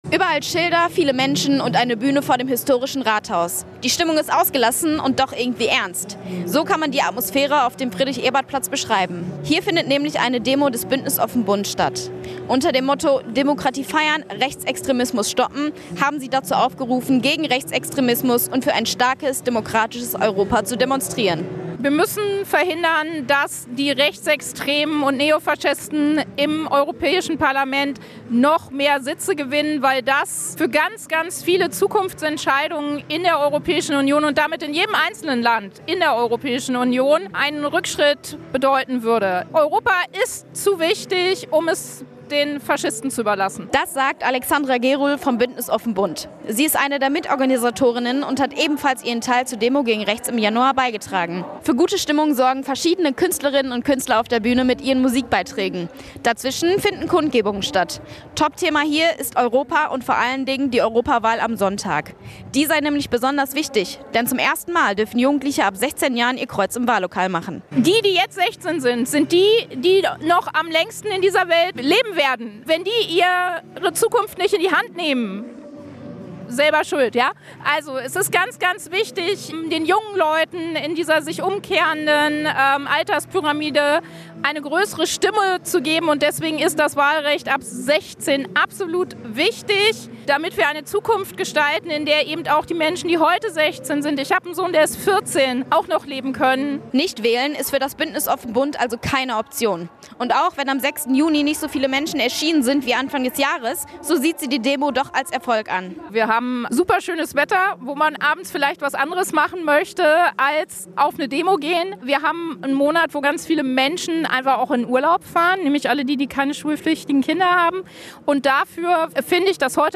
Die Kundgebung war ein bunter Mix aus Musik und Redebeiträgen verschiedener Hagenerinnen und Hagener, die zum Bündnis offen Bunt gehören.
reportage-demo-6.-juni.mp3